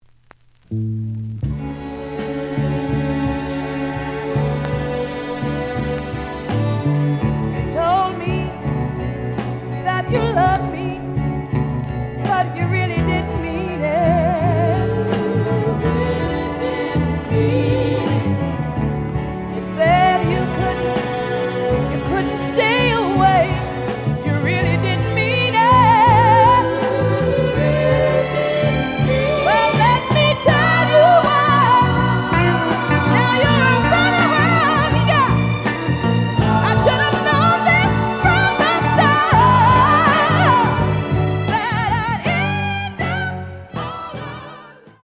●Genre: Soul, 60's Soul